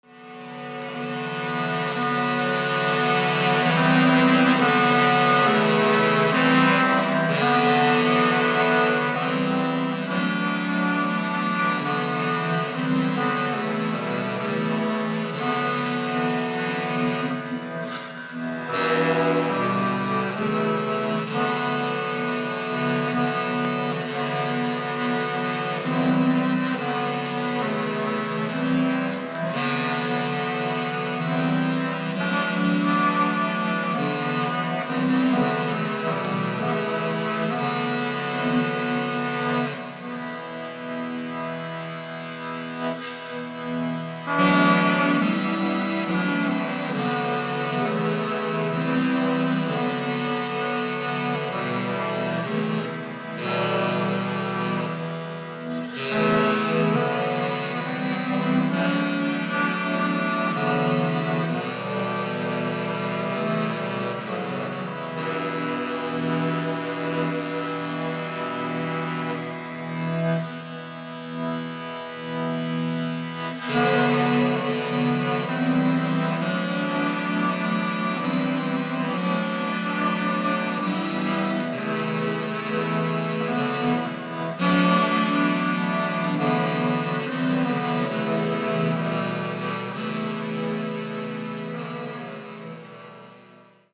現代人が失った心の息吹、それが、古楽。